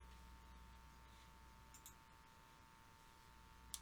The filter set strongly dampens parts of the frequency spectrum in your microphone’s recordings, more specifically only those frequencies that constitute the ground loop noise, leaving other frequencies untouched.
recording-with-filter.m4a